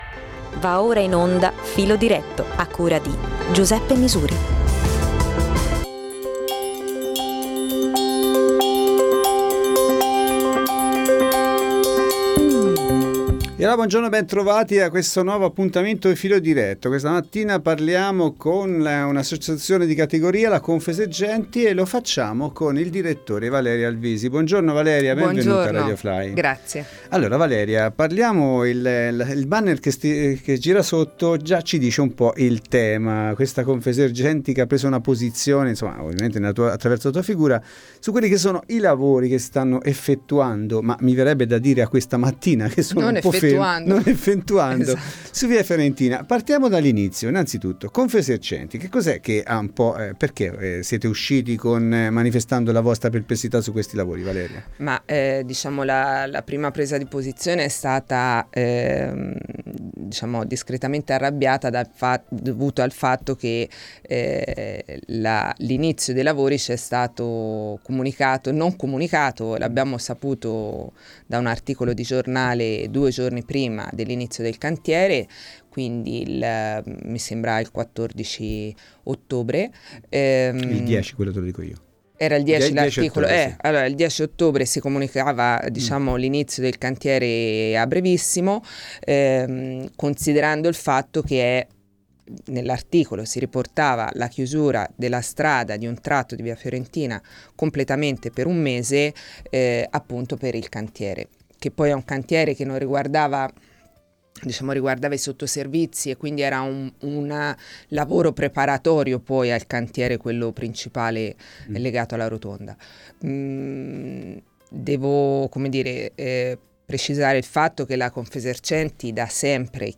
Ne parliamo in studio